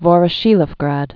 (vôrə-shēləf-grăd, və-rə-shē-ləf-grät)